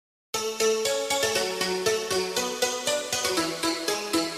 • Качество: 128, Stereo
красивые
электронная музыка
без слов